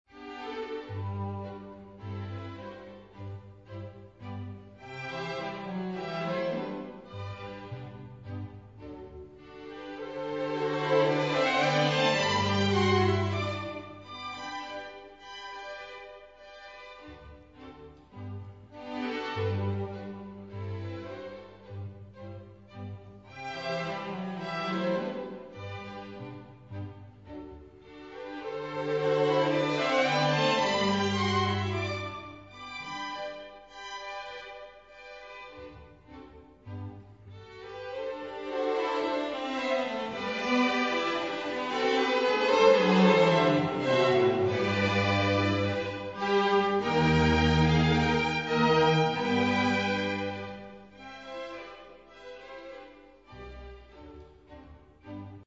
Suita pro smyčce-1877 (III.Andante con moto, Nezávislý komorní orchestr Boemia, dir.